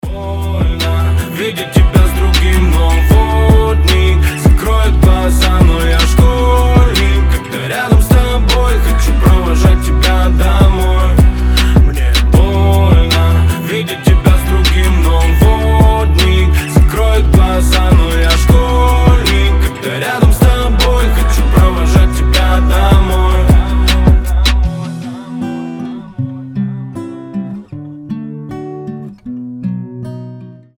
• Качество: 320, Stereo
мужской голос
грустные